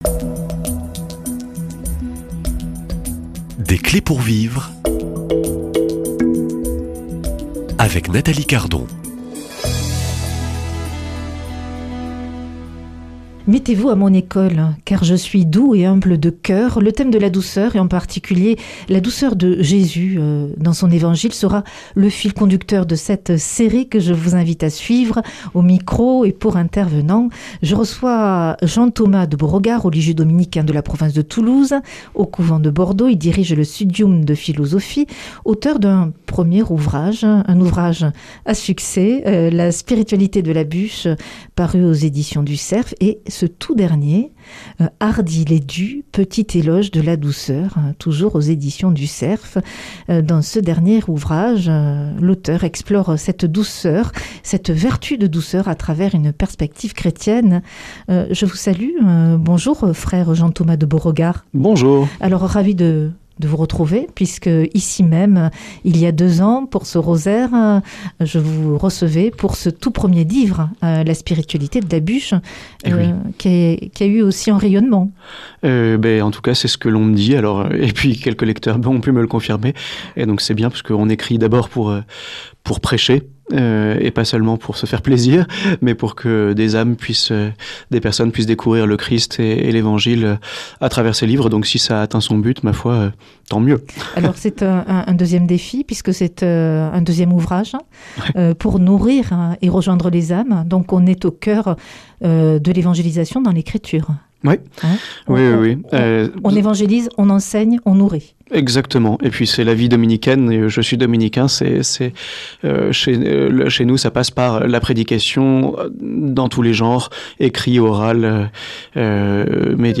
Hardi les doux Petit éloge de la douceur Invité